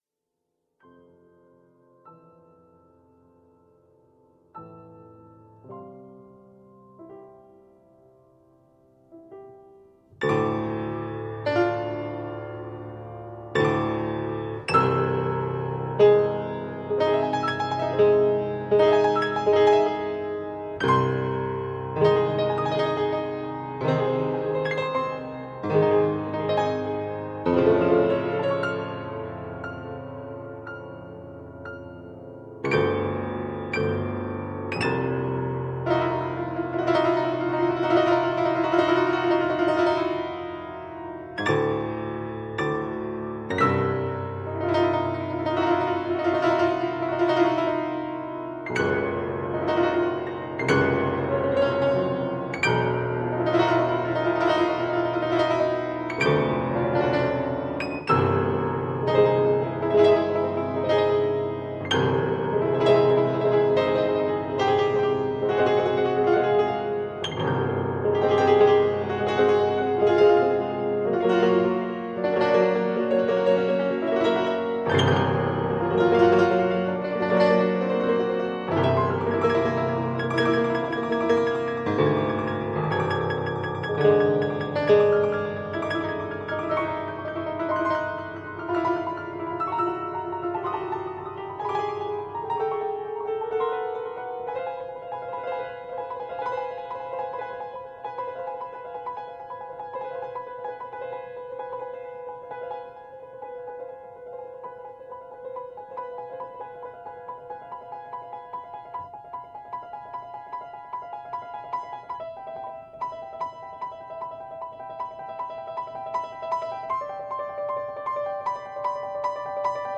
Improvisation-Fragment-2.mp3